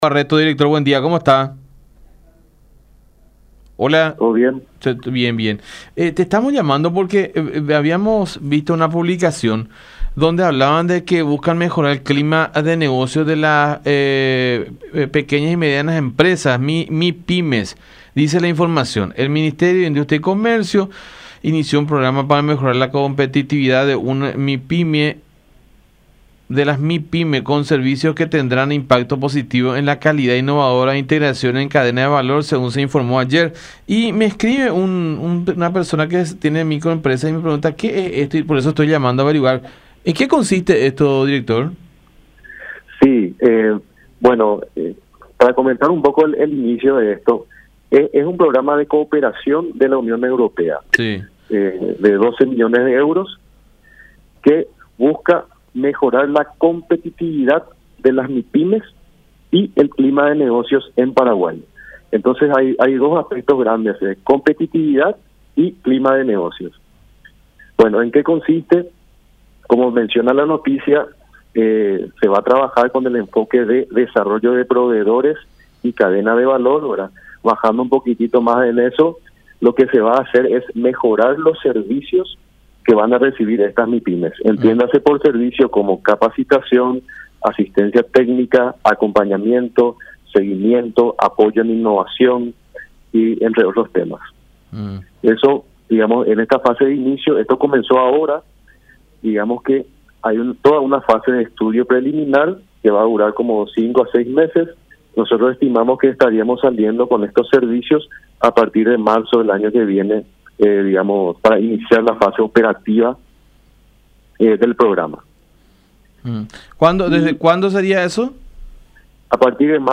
“Es un programa de cooperación de la Unión Europea que busca mejorar el clima de negocios y el enfoque de desarrollo de proveedores”, comentó el director General del Gabinete Técnico del MIC, Máximo Barreto, en contacto con La Unión.